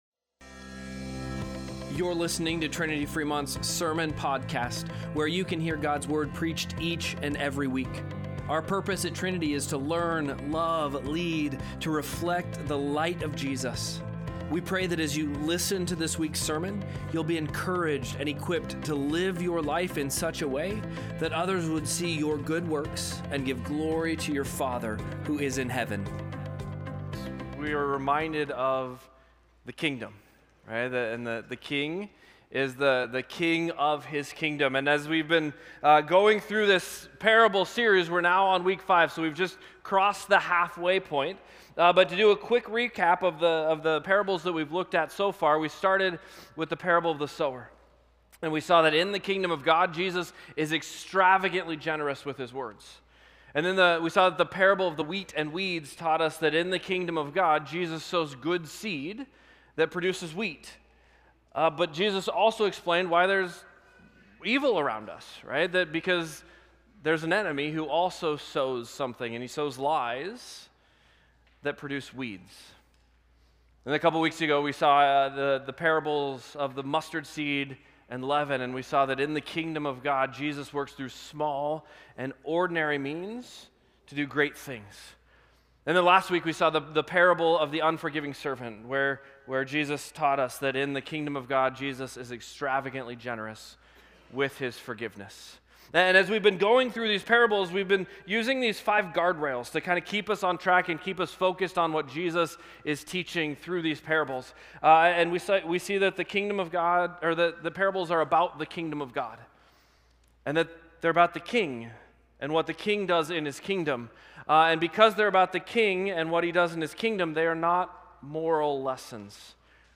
2-1-Sermon-Podcast.mp3